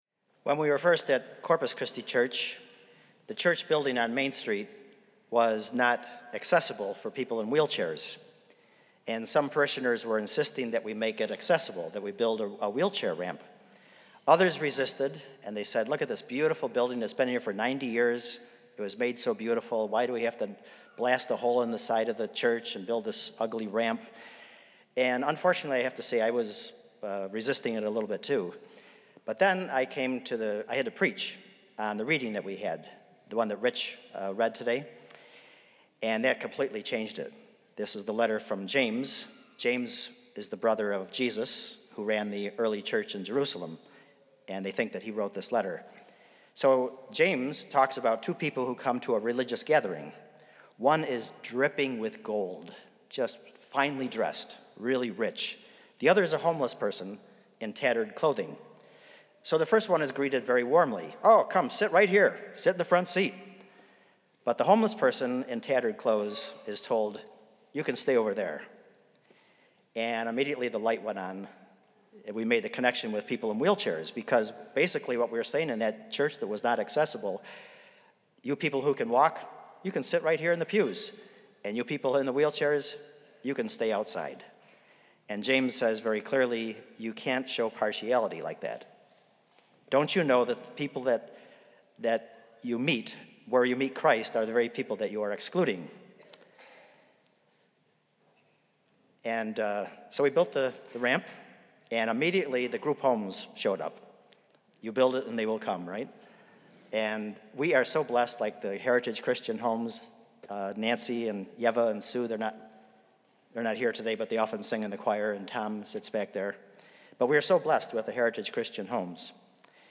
Spiritus Christi Mass September 9th, 2018
The Spiritus Christi Gospel choir sings “There Is No Way”.